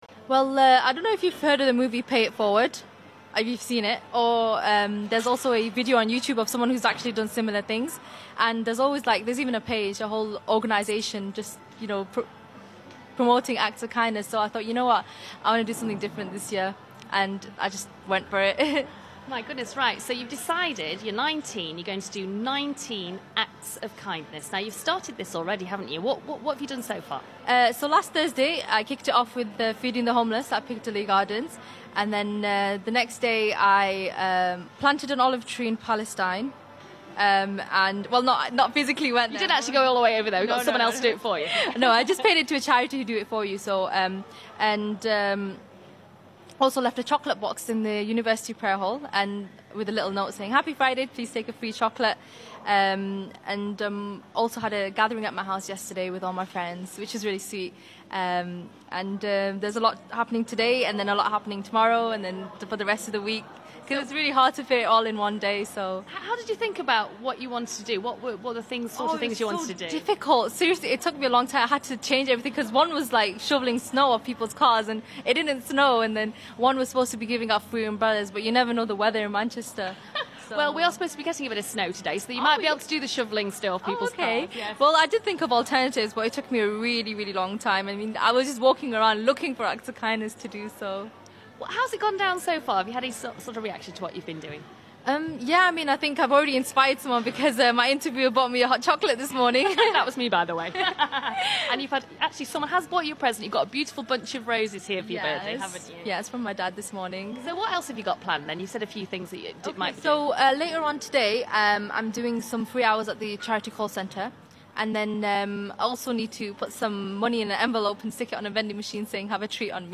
at the University of Manchester Students' Union.